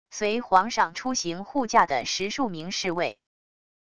随皇上出行护驾的十数名侍卫wav音频生成系统WAV Audio Player